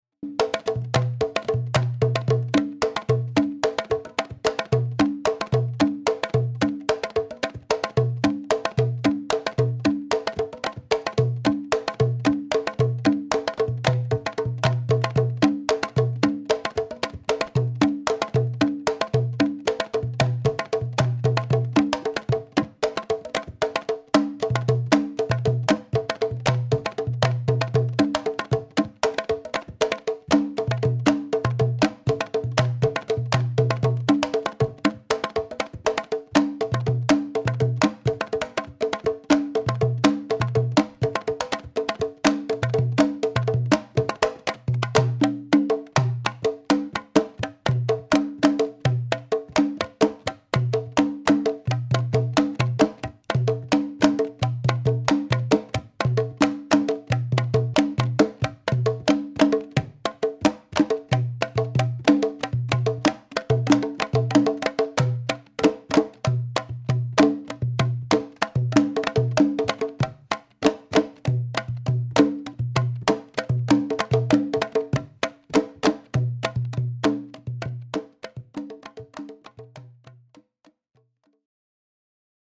8-piece rumba group
quinto